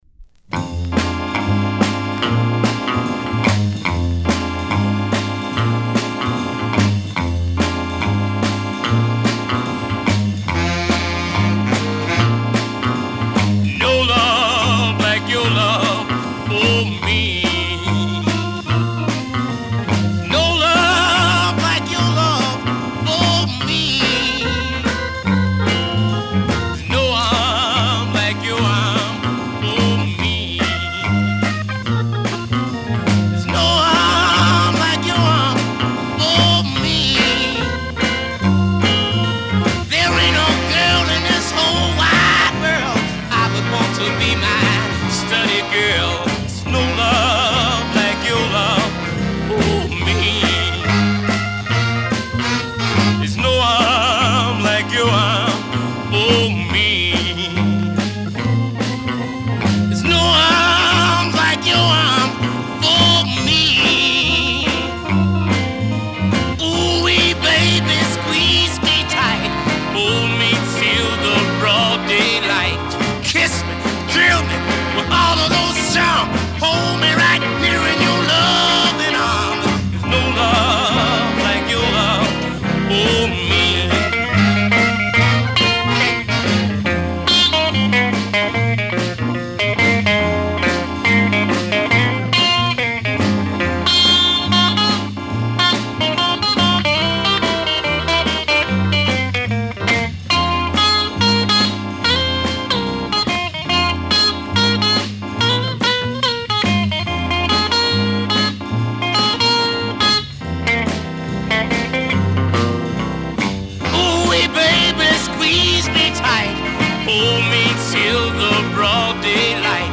ダークで憂鬱なムードを湛えたブルースR&B。激烈シャウトと憂鬱なオルガンの組み合わせが妙味。
[Popcorn] [Comped] [NEW]